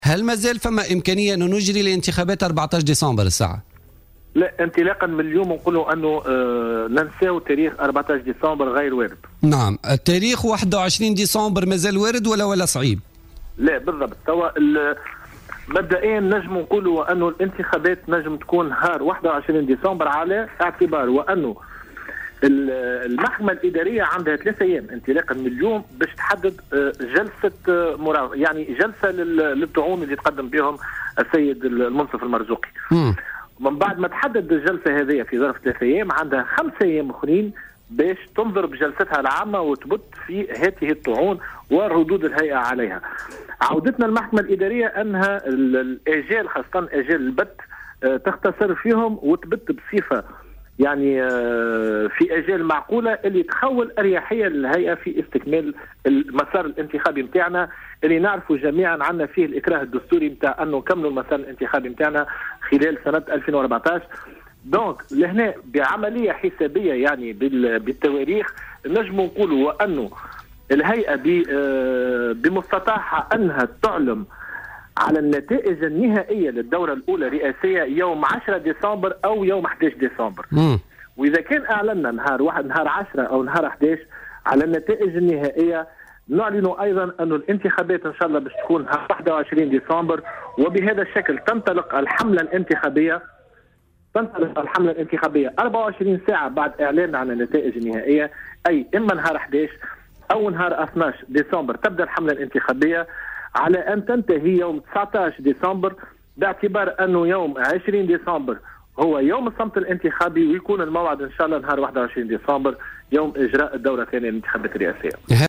استبعد عضو الهيئة العليا المستقلة للانتخابات،نبيل بافون في مداخلة له في برنامج "بوليتيكا" إجراء الدور الثاني للانتخابات الرئاسية يوم 14 ديسمبر 2014.